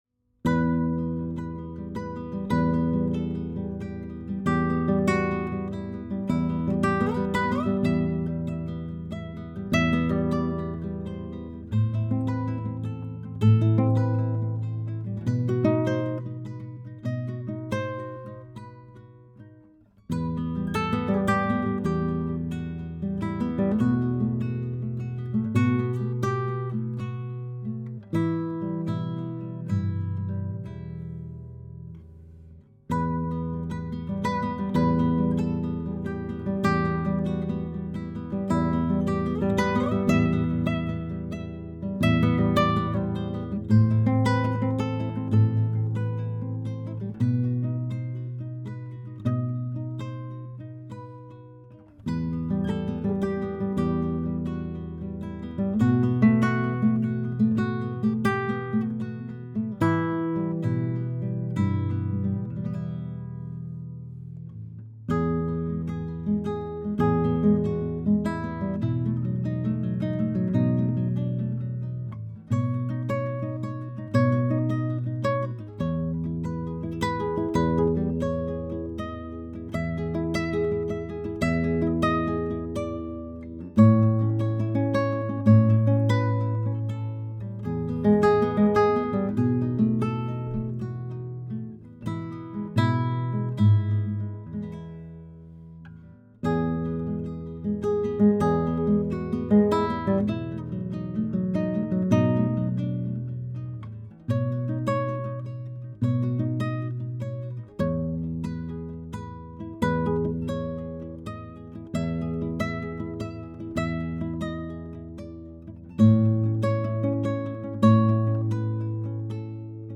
Chicago Classical Guitarist 1
This Chicago Classical Guitarist provides live instrumental background music for parties, banquets, weddings, restaurants, or any special gathering that calls for a lively and elegant ambience.
His repertoire includes a savory mix of Latin American folk and popular styles, and classical guitar music of Spanish and Latin American origin.